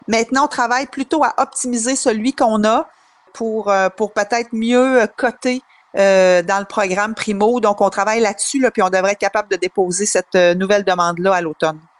Depuis, la Ville a tout de même trouvé des solutions comme l’a expliqué la mairesse.